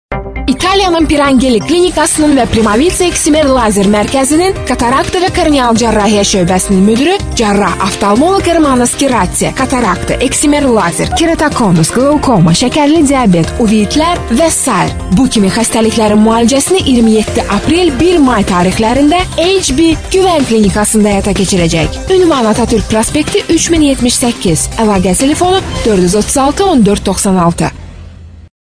Yabancı Seslendirme Kadrosu